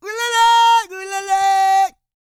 E-CROON 206.wav